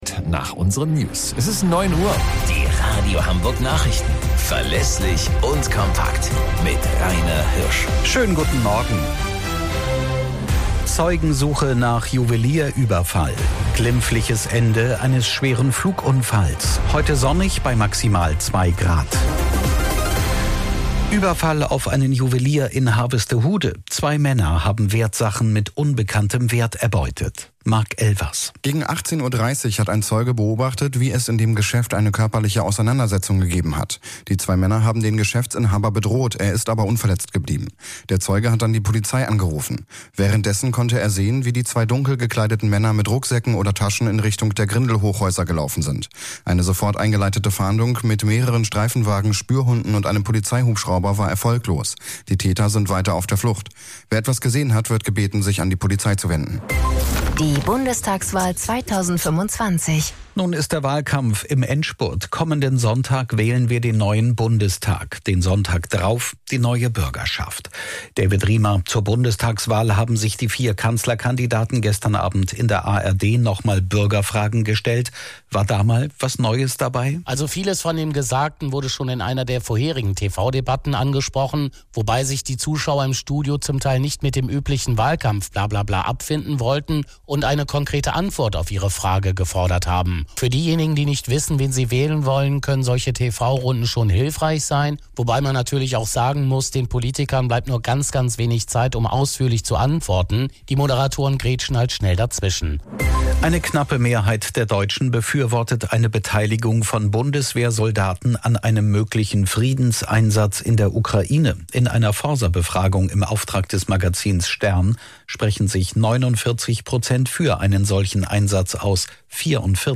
Radio Hamburg Nachrichten vom 18.02.2025 um 09 Uhr - 18.02.2025